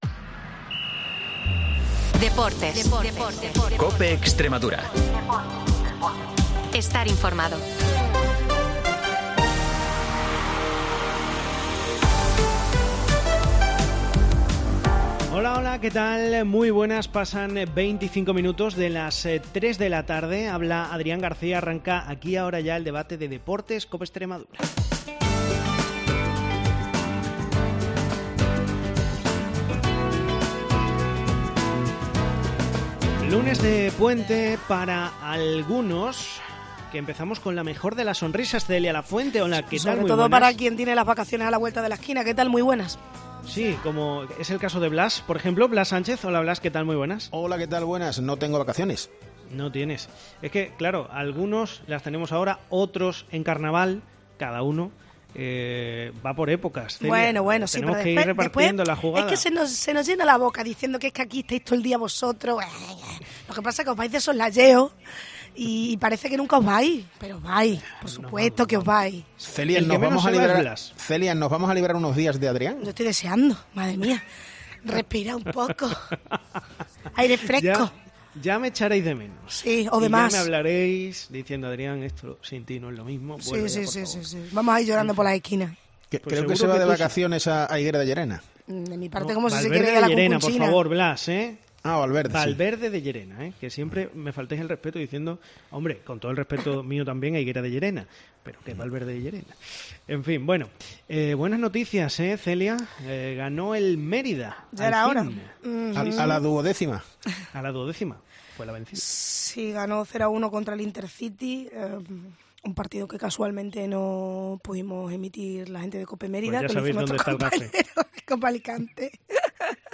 El debate de deportes de COPE Extremadura